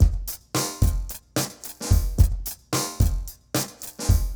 RemixedDrums_110BPM_25.wav